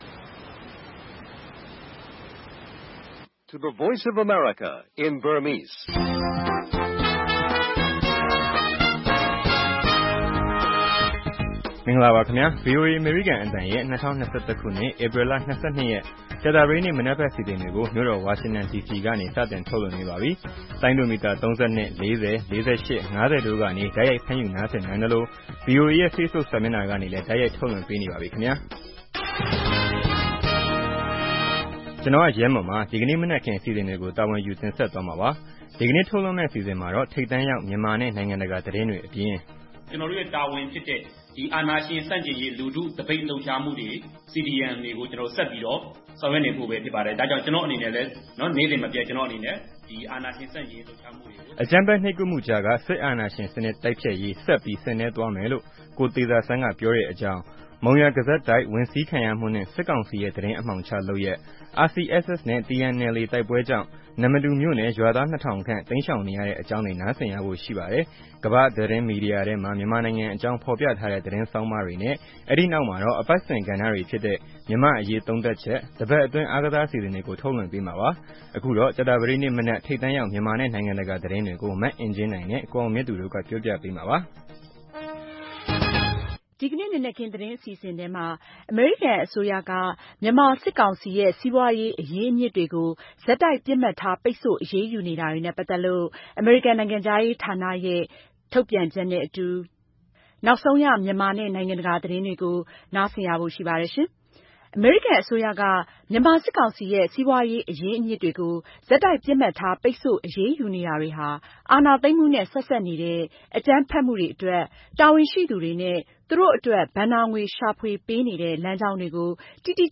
ဗွီအိုအေရဲ့ ကြာသာပတေးနေ့ မနက်ပိုင်း ရေဒီယိုအစီအစဉ်ကို ရေဒီယိုက ထုတ်လွှင့်ချိန်နဲ့ တပြိုင်နက်ထဲမှာပဲ Facebook ကနေလည်း တိုက်ရိုက် နားဆင်နိုင်ပါတယ်။